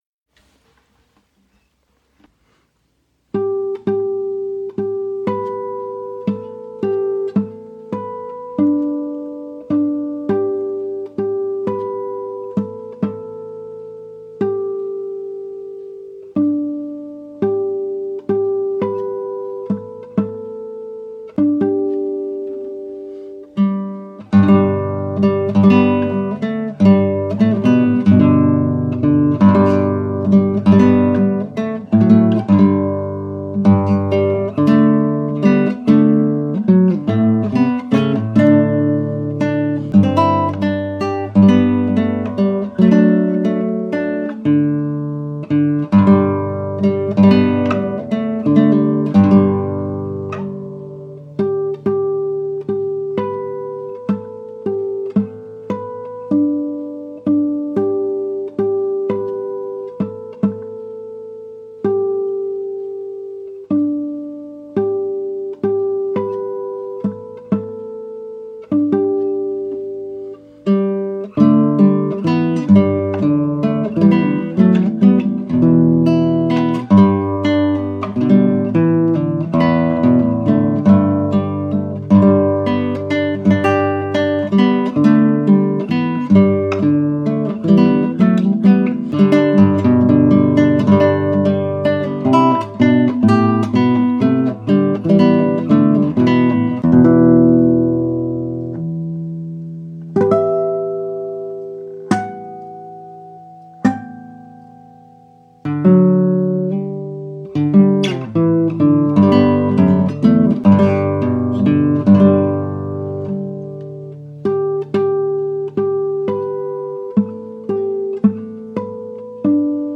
A classical guitar setting for "Adam-ondi-Ahman."
Voicing/Instrumentation: Guitar , Guitar Chords Available We also have other 7 arrangements of " Adam Ondi Ahman ".
Recordings are done on my phone in the living room...often with some funny background noise!